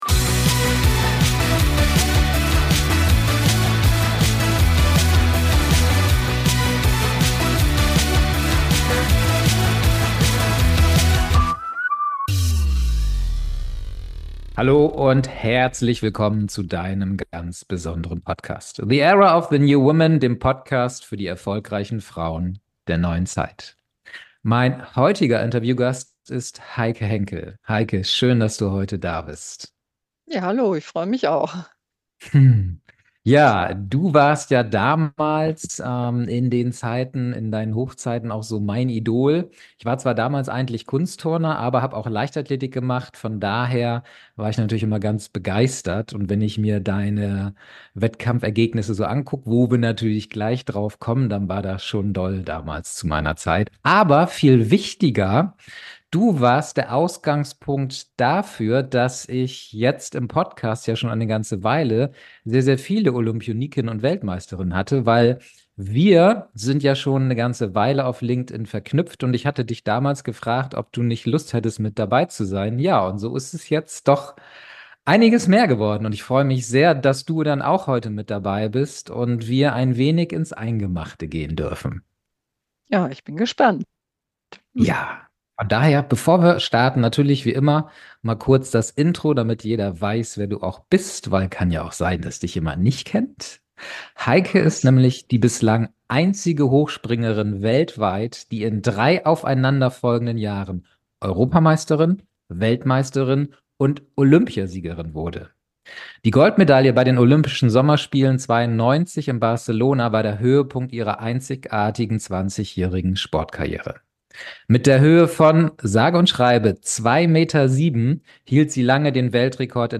#044 Eine Grand Dame des deutschen Sports - Das Interview mit Olympiasiegerin Heike Henkel ~ The Era of the New Women Podcast